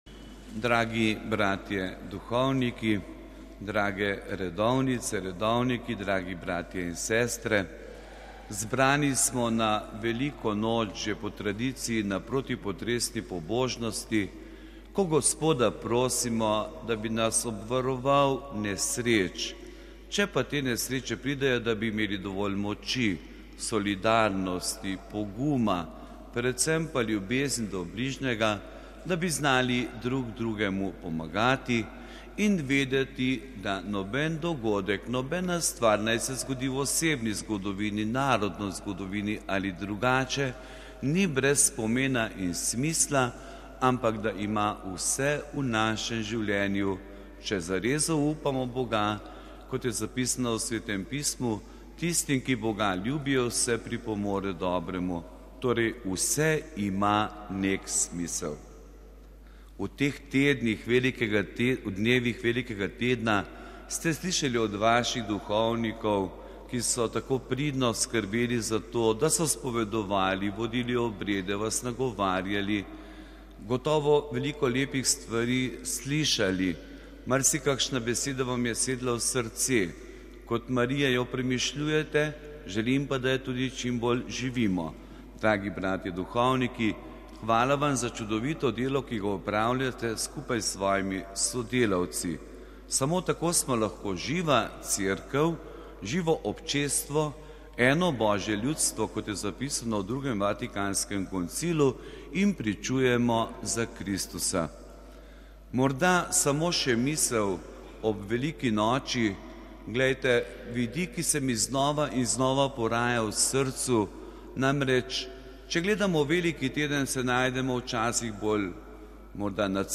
LJUBLJANA (ponedeljek, 21. april 2014, RV) – Na veliko noč popoldne je v cerkvi sv. Jožefa v Ljubljani potekala zaobljubljena 'potresna pobožnost' za ljubljanske župnije. Pobožnost je vodil ljubljanski pomožni škof Anton Jamnik. Letos je že 119. obletnica potresa, ki je močno prizadel Ljubljano.